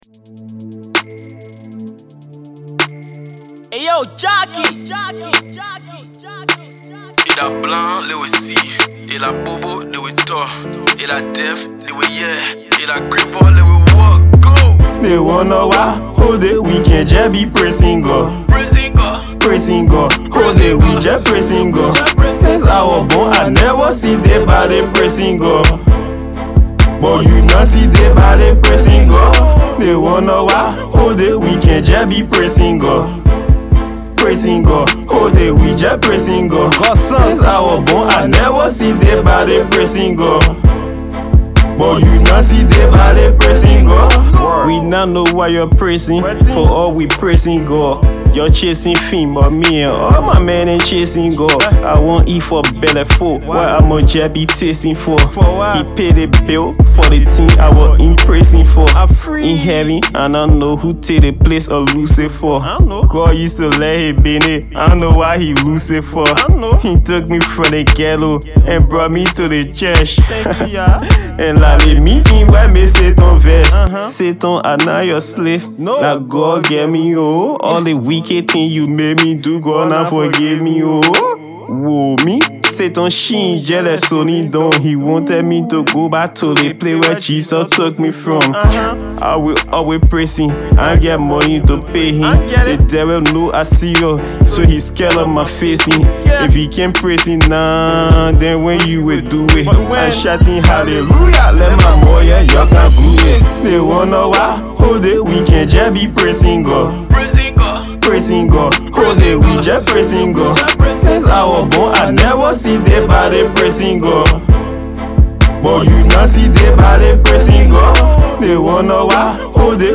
Gosple
fire blazing colloquial tune